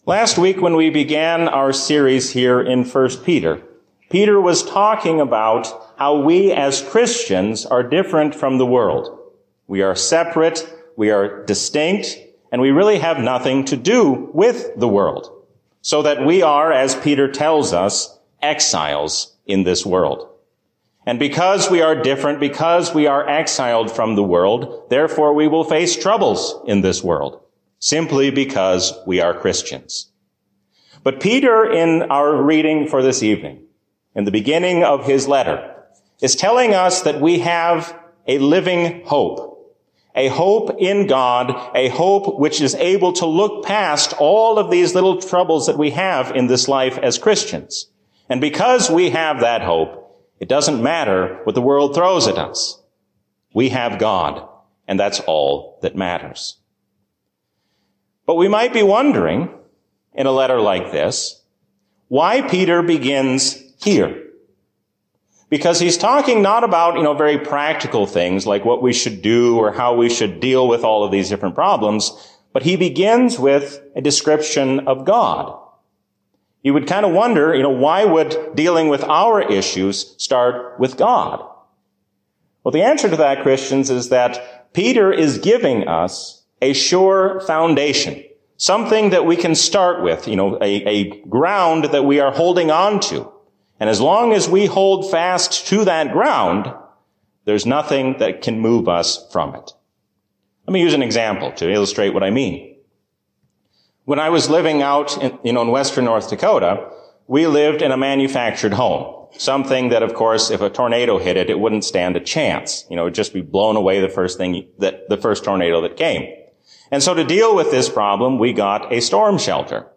A sermon from the season "Advent 2023." We can trust all the promises of God, because we have God's favor in His Son.